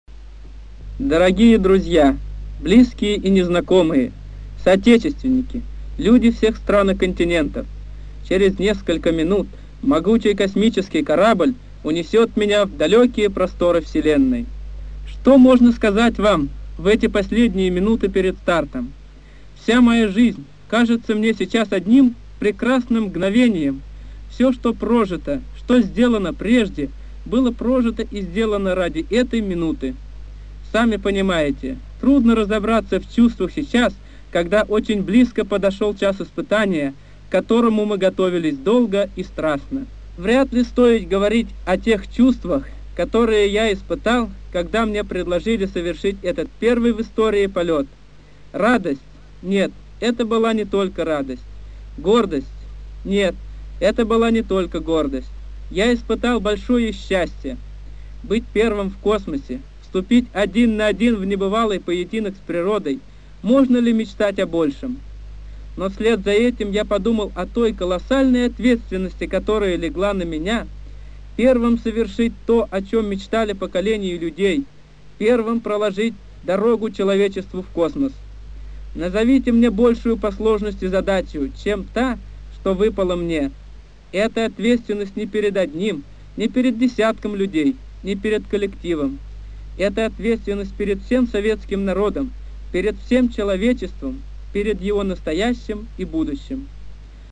Ю.А. Гагарин. Выступление перед стартом.
Gagarin_rech_pered_startom.mp3